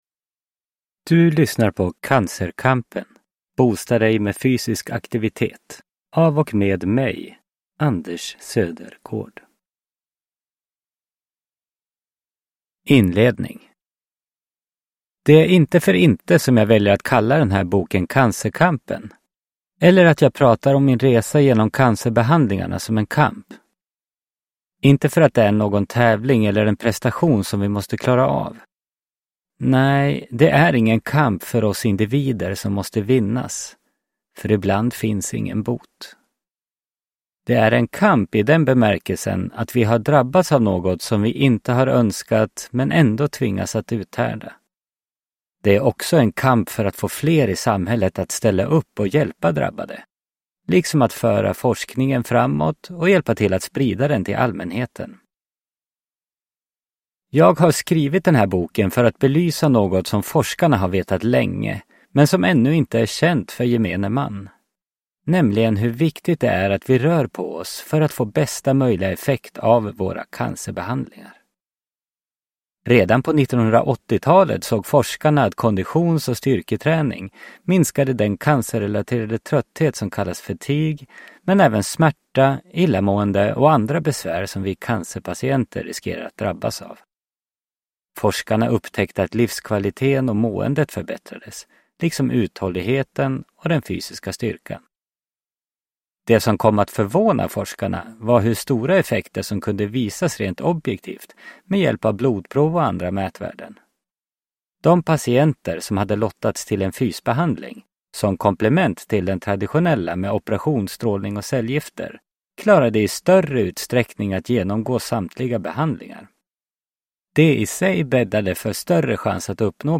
Cancerkampen : boosta dig med fysisk aktivitet – Ljudbok – Laddas ner